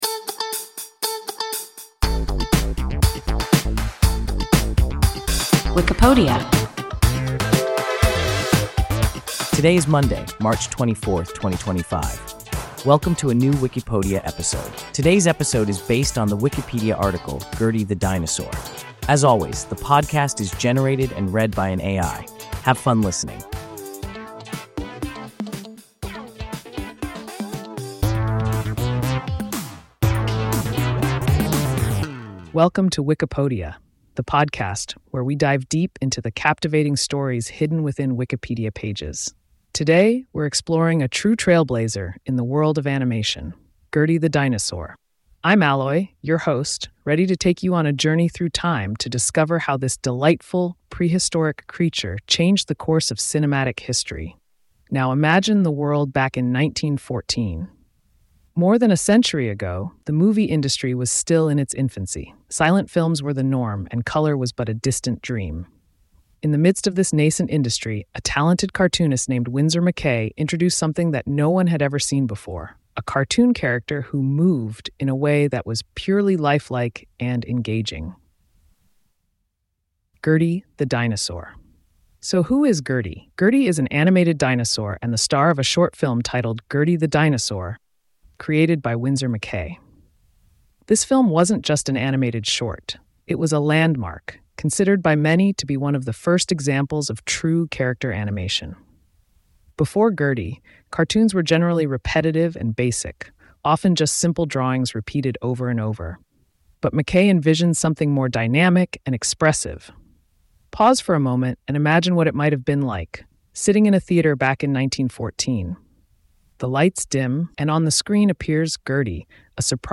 Gertie the Dinosaur – WIKIPODIA – ein KI Podcast